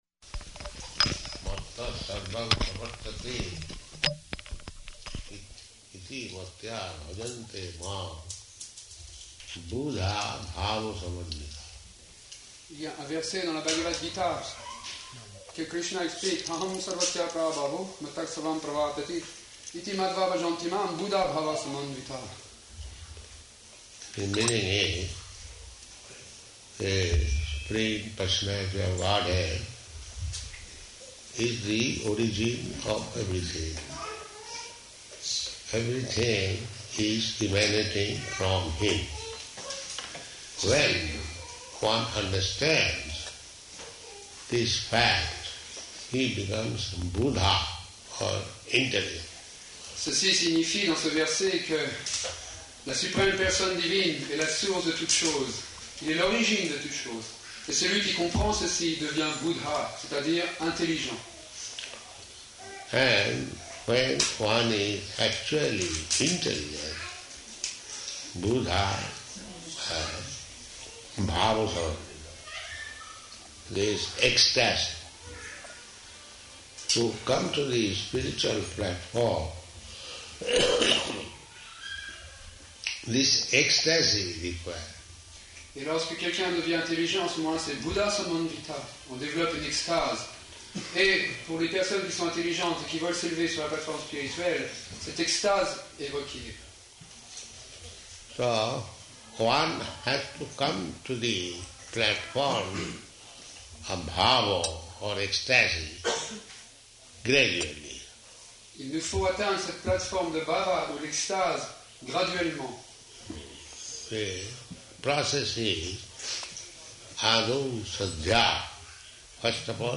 Location: New Māyāpur
[translated throughout into French]
[devotee mentions aside about technical recording problem] This faith is the beginning, ādau śraddhā.